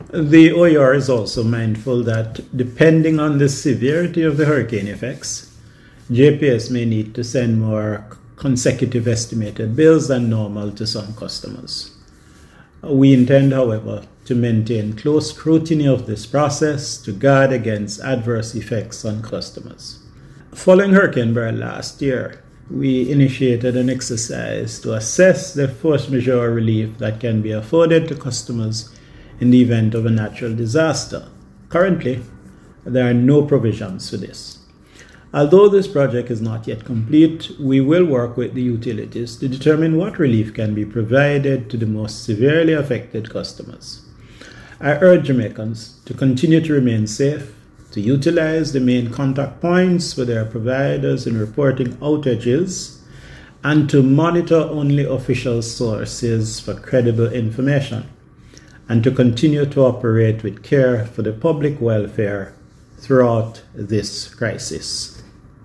Audio clip – OUR DG on JPS Billing after Melissa
OUR-DG-on-JPS-Billing-after-Melissa.mp3